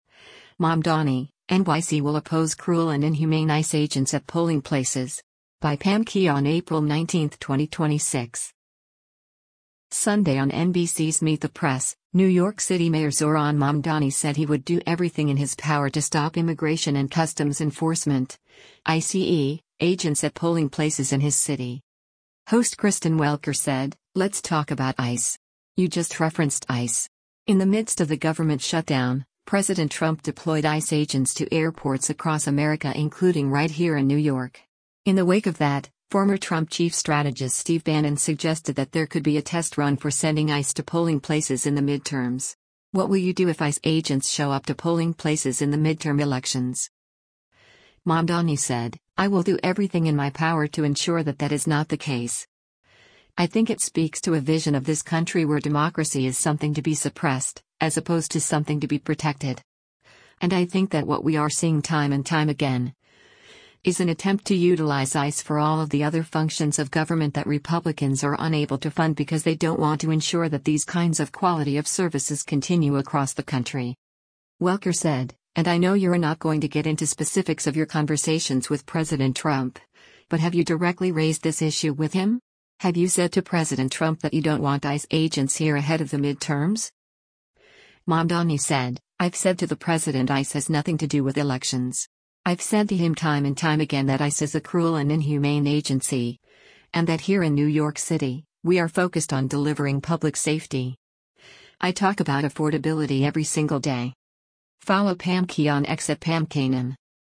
Sunday on NBC’s “Meet the Press,” New York City Mayor Zohran Mamdani said he would do everything in his power to stop Immigration and Customs Enforcement (ICE) agents at polling places in his city.